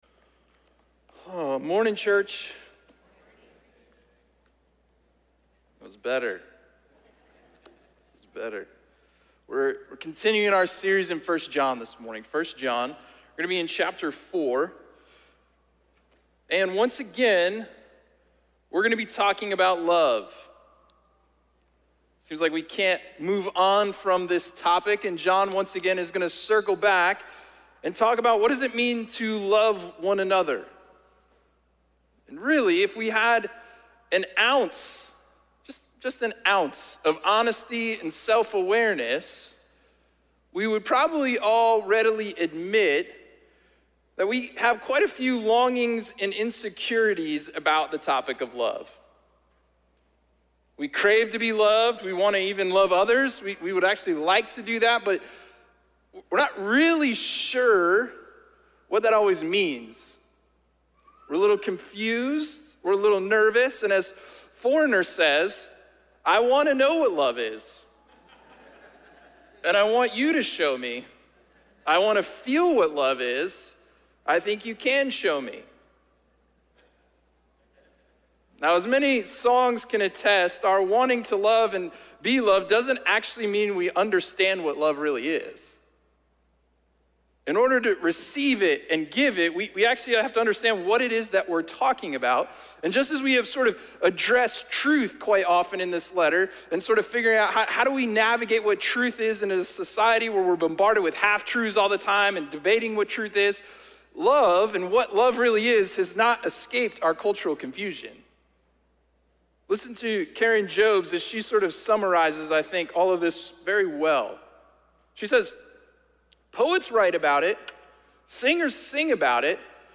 A message from the series "Family Meeting."